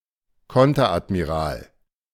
Konteradmiral (German pronunciation: [ˈkɔntɐʔatmiˌʁaːl]